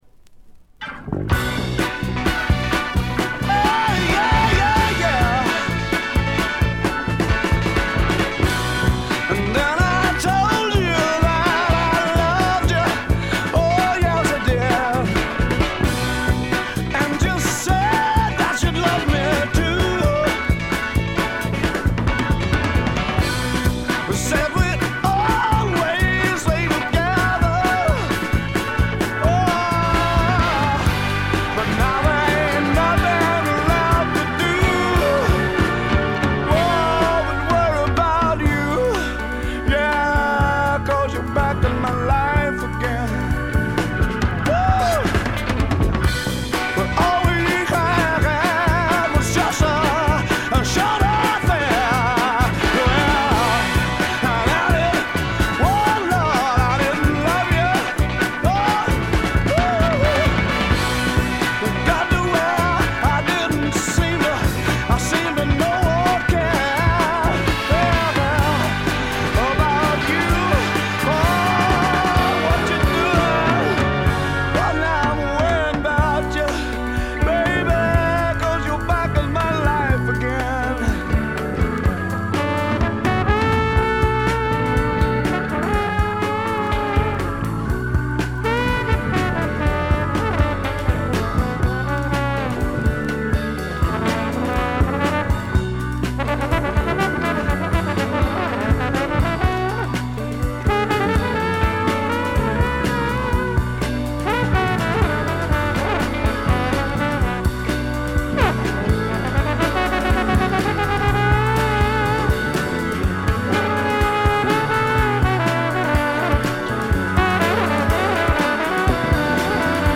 部分試聴ですがチリプチ少々程度。
スワンプ・ロックの基本定番！！！
試聴曲は現品からの取り込み音源です。
January 1971 Olympic Sound Studios, Barnes, London